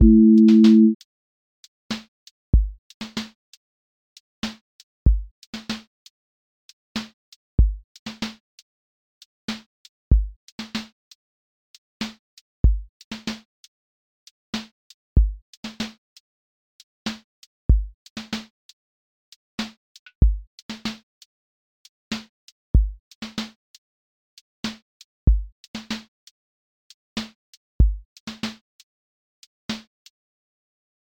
QA Listening Test r&b Template: rnb_pocket
Generate a 30-second r&b groove with warm chords, ghost snares, smooth bass support, and a subtle verse to chorus lift.
• voice_kick_808
• voice_snare_boom_bap
• voice_hat_rimshot